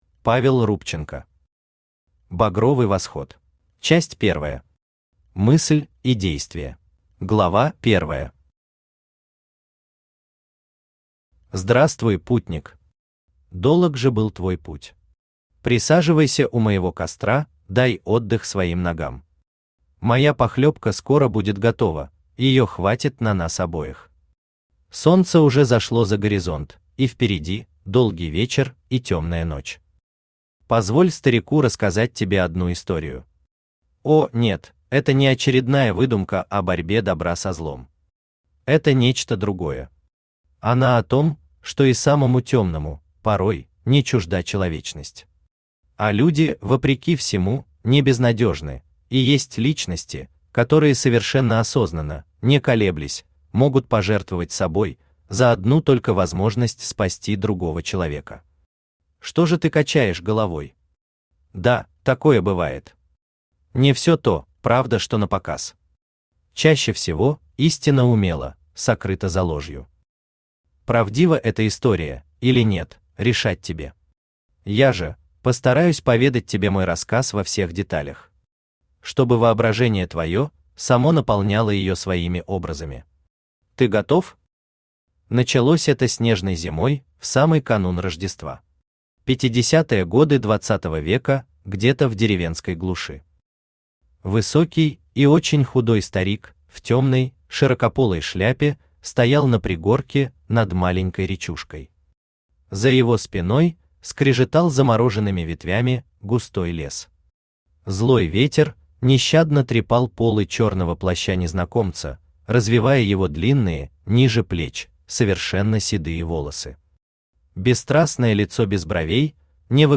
Аудиокнига Багровый восход | Библиотека аудиокниг
Aудиокнига Багровый восход Автор Павел МИХАЙЛОВИЧ РУБЧЕНКО Читает аудиокнигу Авточтец ЛитРес.